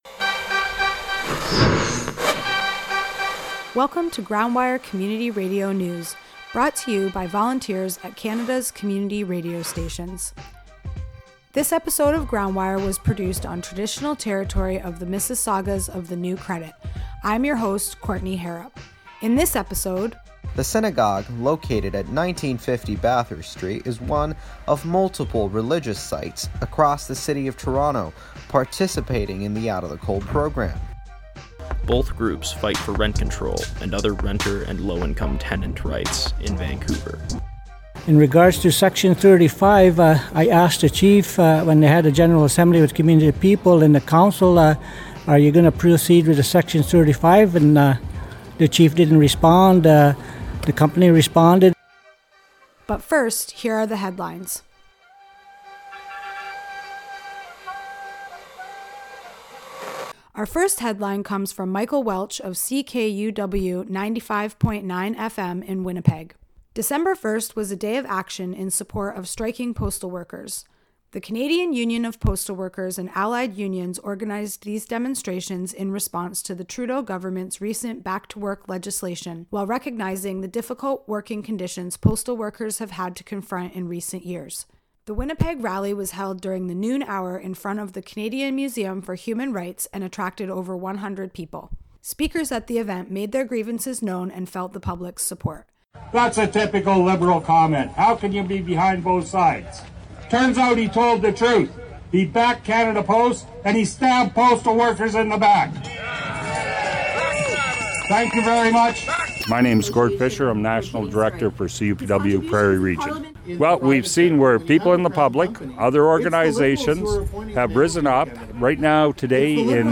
Community Radio News from Coast to Coast to Coast